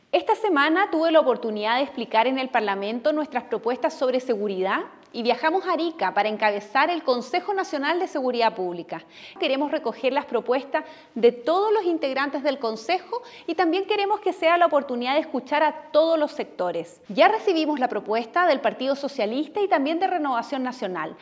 En esta línea, la ministra Siches aseguró que han recibido propuestas para modificar o complementar el Plan y que todo se tomará en cuenta.